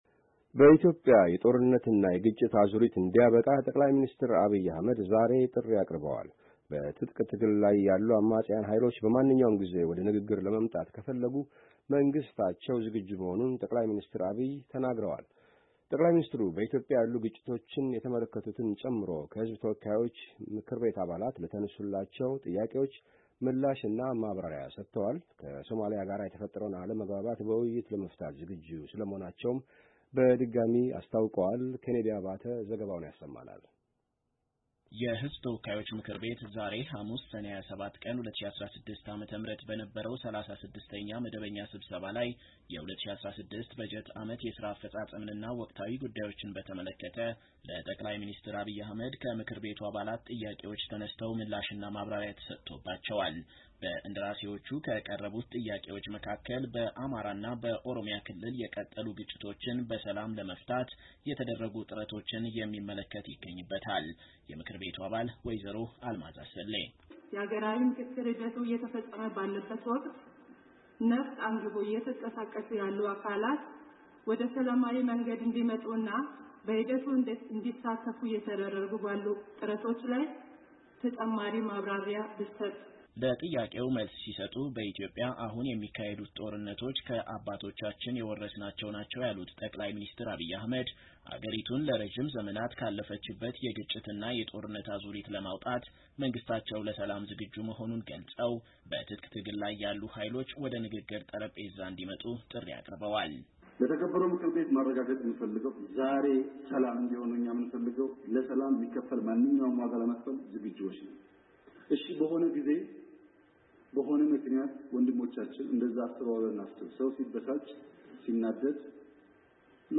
ጠቅላይ ሚኒስትሩ፣ በኢትዮጵያ ያሉ ግጭቶችን ጨምሮ ዛሬ ሐሙስ ከሕዝብ ተወካዮች ምክር ቤት አባላት በተለያዩ ጉዳዮች ዙሪያ ለተነሡላቸው ጥያቄዎች ምላሽ እና ማብራሪያ ሰጥተዋል፡፡